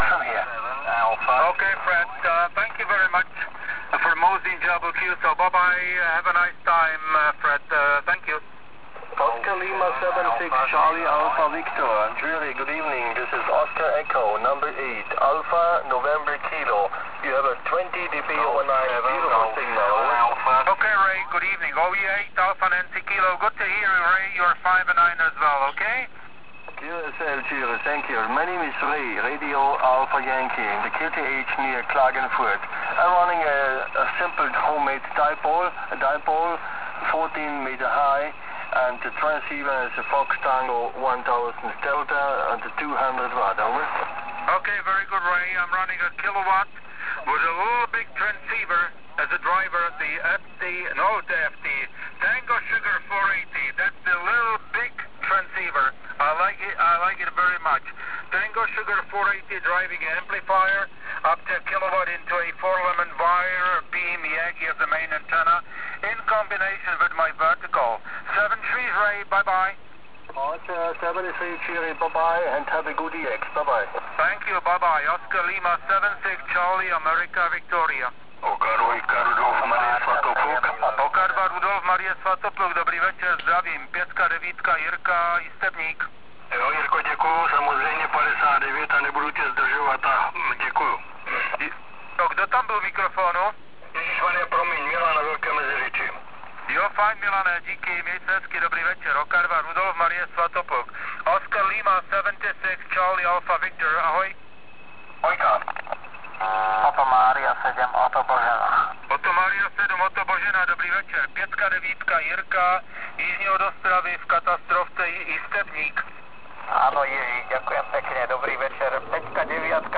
OL76CAV 80m SSB (*.wav 4 MB) Ale mysl�m, �e pod�kov�n� pat�� v�em.